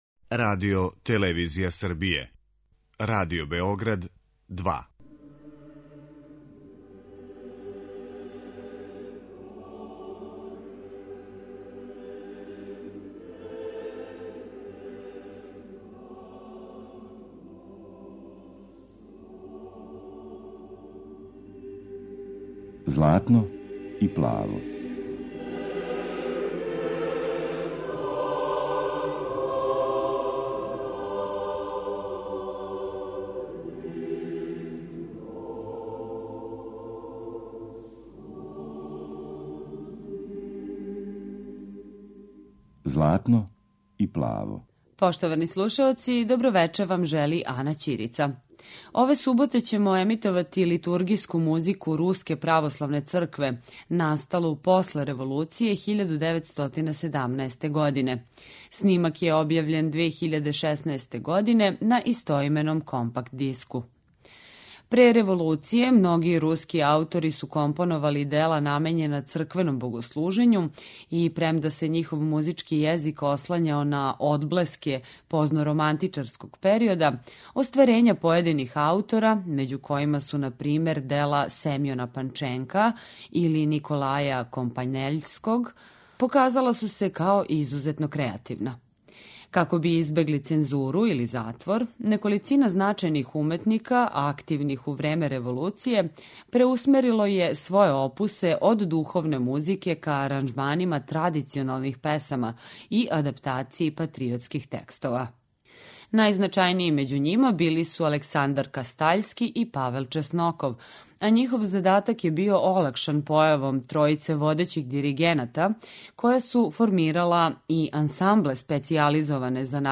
Литургијска музика Руске православне цркве после револуције 1917. године